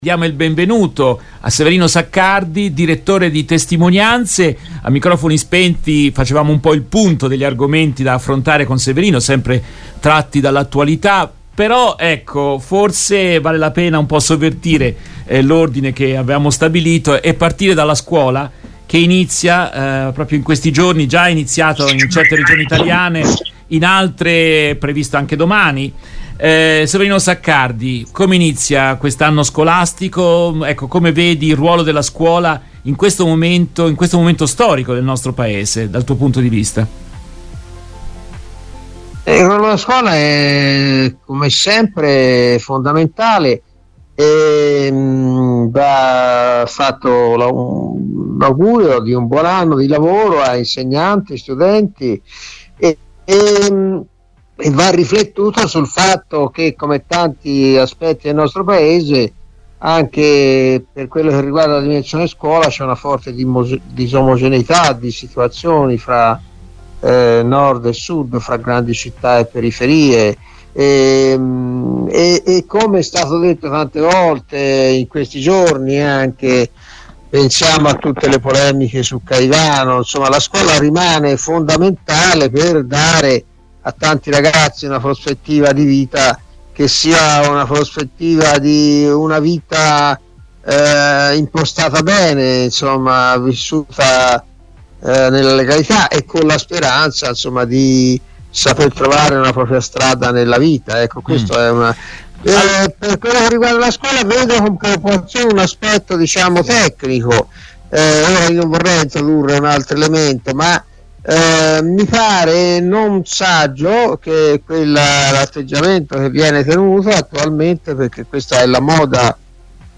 Nel corso della diretta RVS del 14 settembre 2023